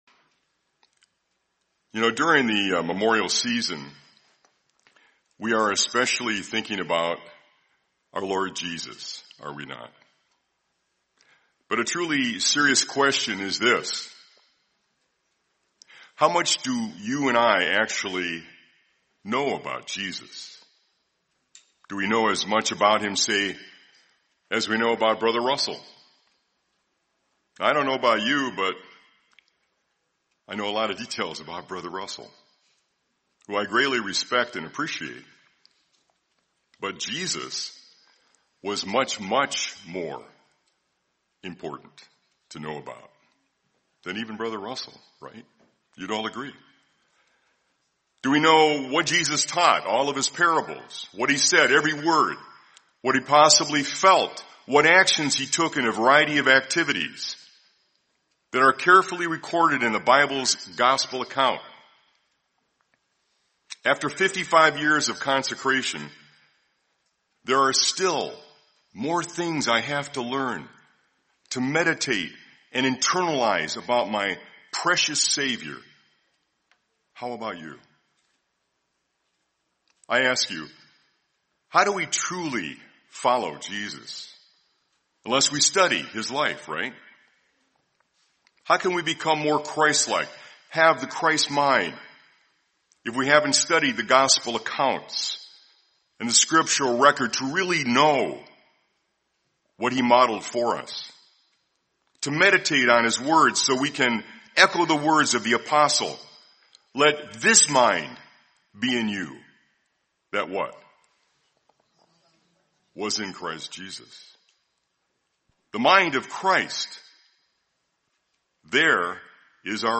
Series: 2025 Wilmington Convention